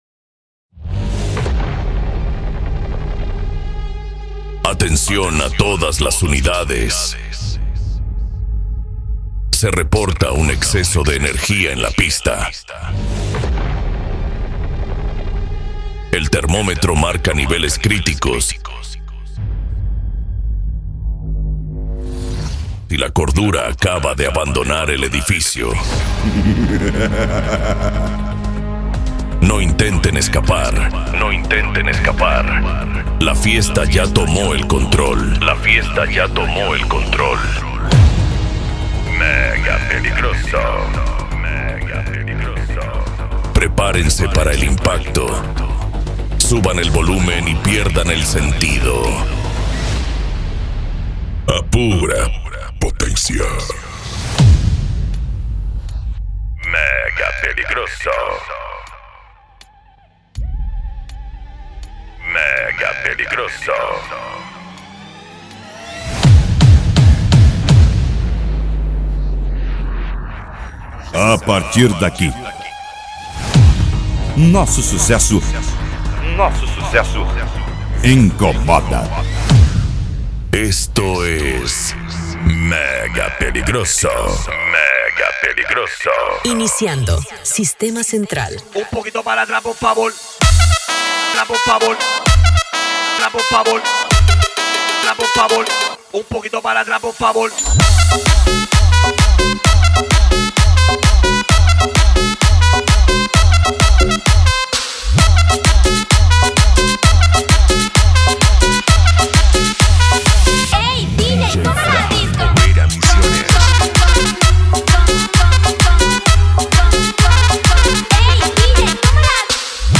Cumbia
Modao
Remix